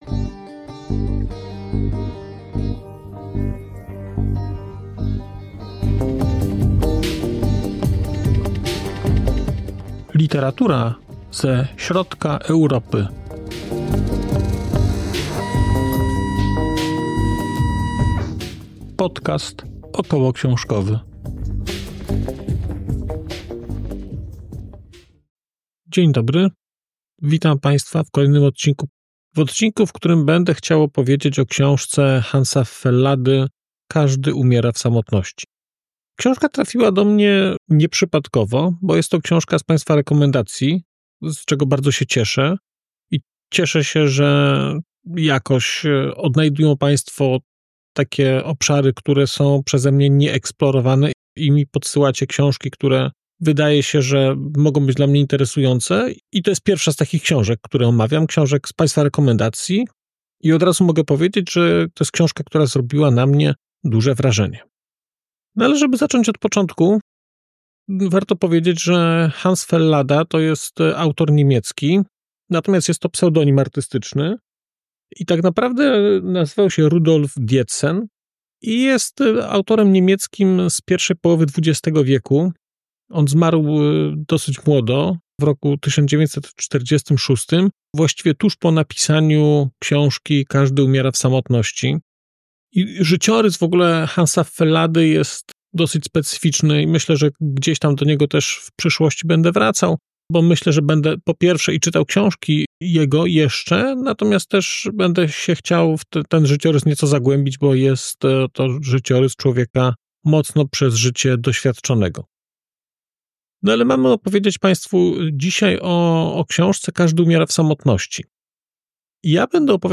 🔧 odcinek zremasterowany: 7.04.2025